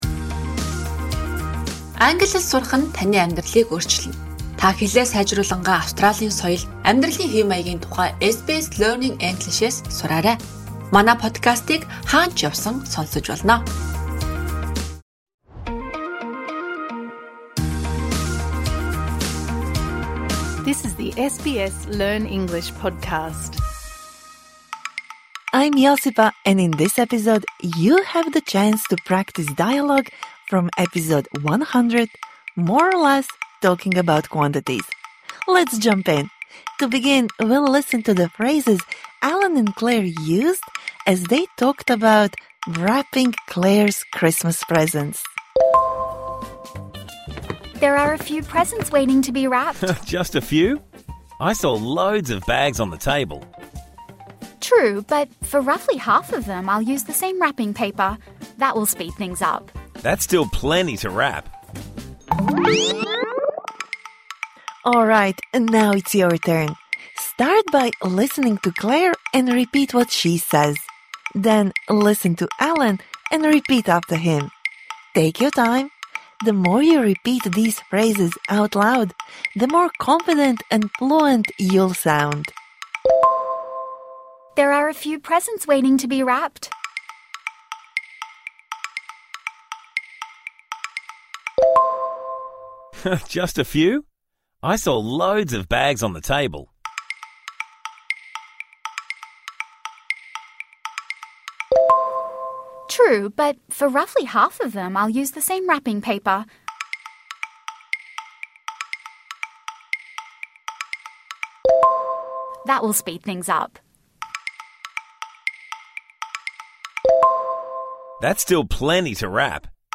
This bonus episode provides interactive speaking practice for the words and phrases you learnt in #100 More or less? Talking about quantities